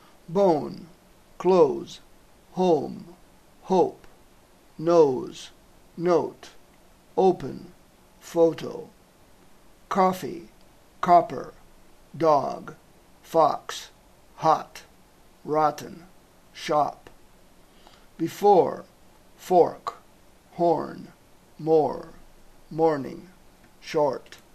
La vocal O es pronuncia /óu/ quan es troba davant de consonant més vocal, i /ò/ quan no s'hi troba.
També sona /ó/ davant de la R, però no davant el grup ORR (vegeu més avall).